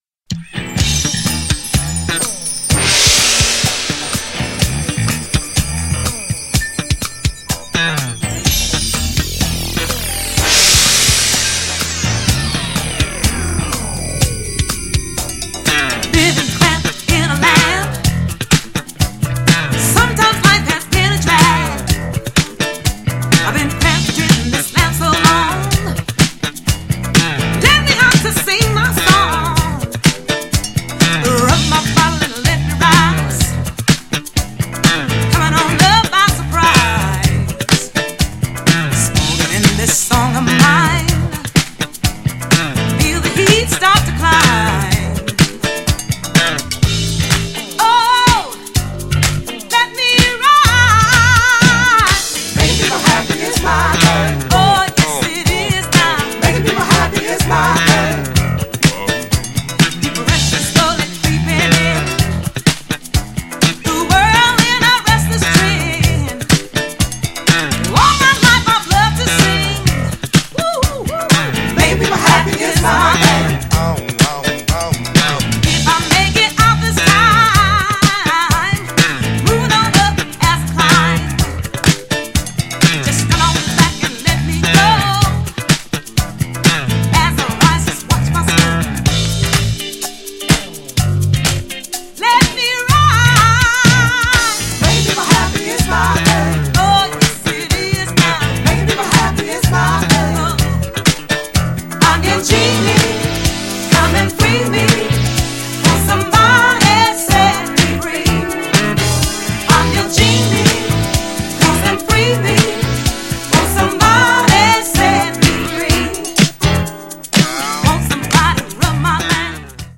GENRE Dance Classic
BPM 121〜125BPM